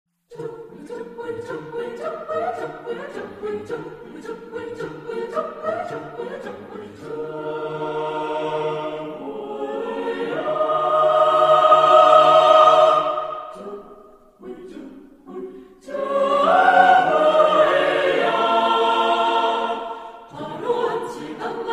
• Voicing: satb
• Accompaniment: a cappella